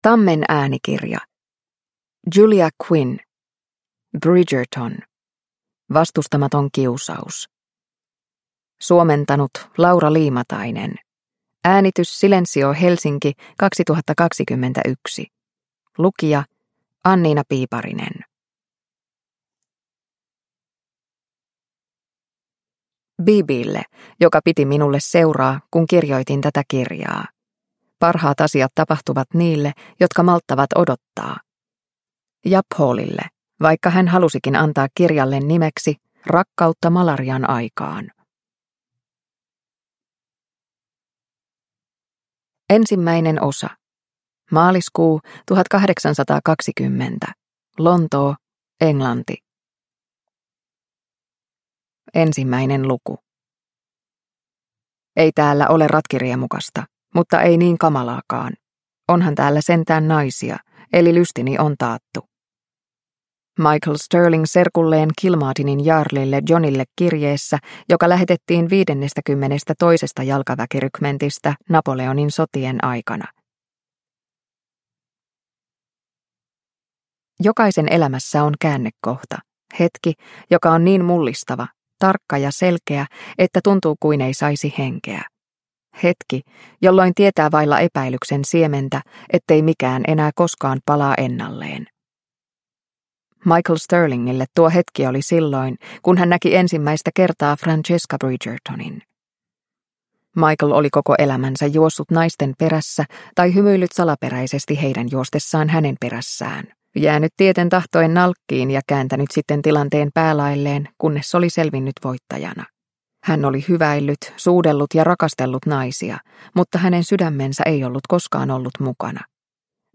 Bridgerton: Vastustamaton kiusaus – Ljudbok – Laddas ner